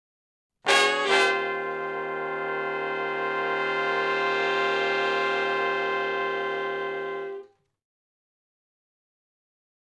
035 Shf Blus Thick End Chds [bII, I] (Eb).wav